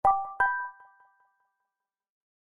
Samsung Galaxy Bildirim Sesleri - Dijital Eşik
Charming Bell
charming-bell.mp3